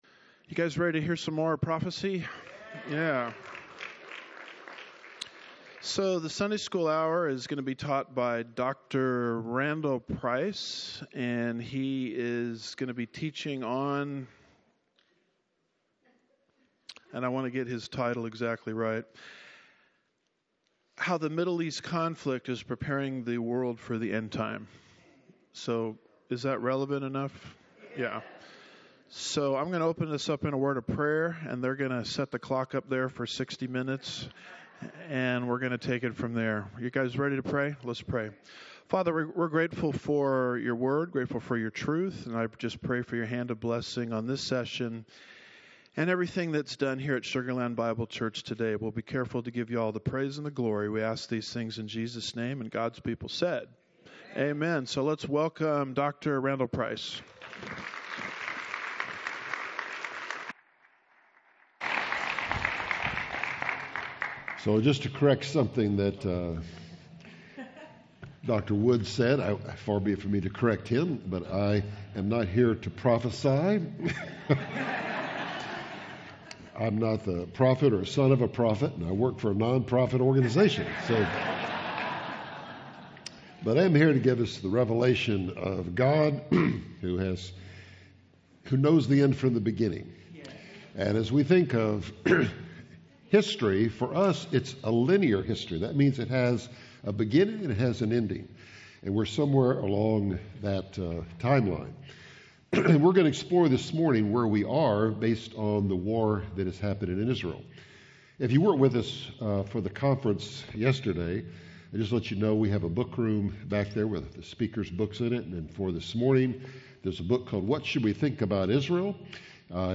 Sermons
2025 Prophecy Conference